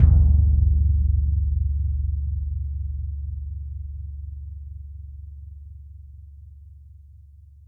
grancassa.wav